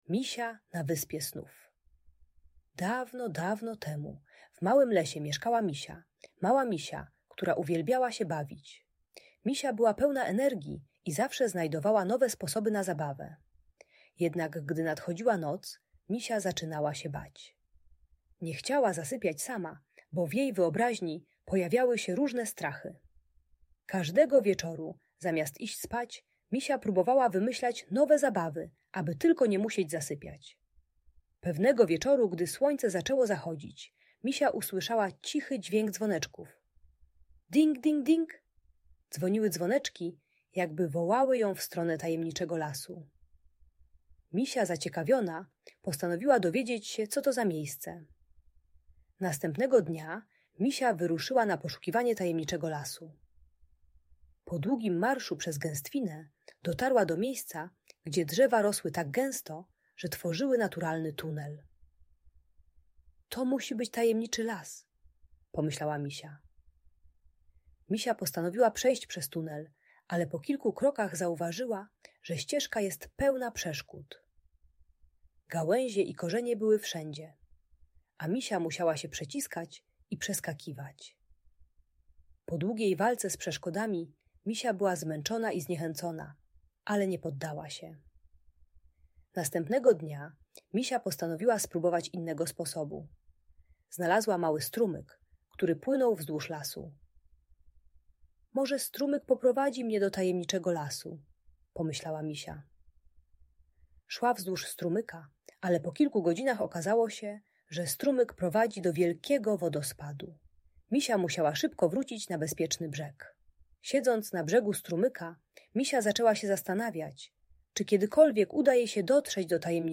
Historia Misi na Wyspie Snów - Audiobajka dla dzieci